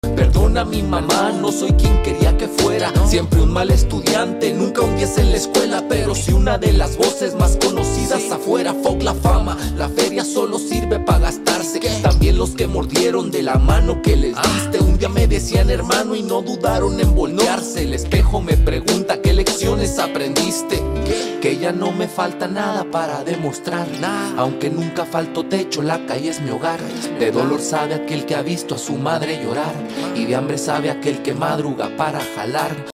Campanadas y pistolas